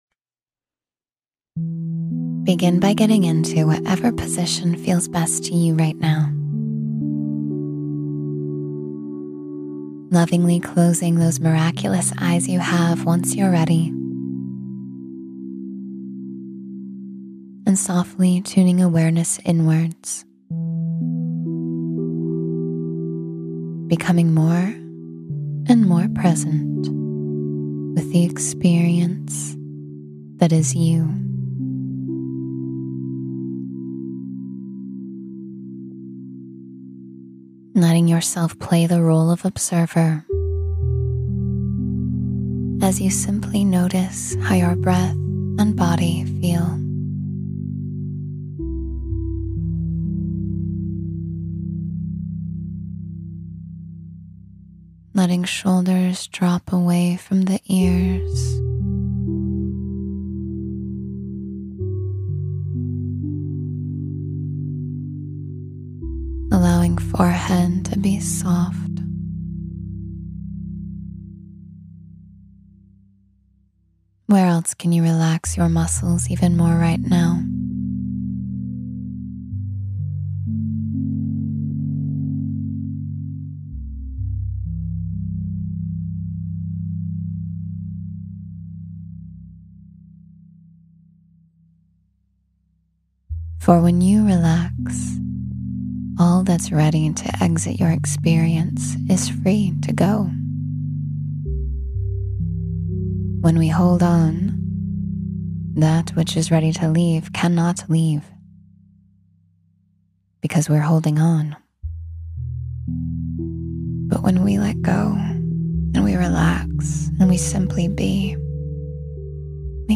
Let Go and Just Be: Surrender to Stillness — Meditation for Calm and Clarity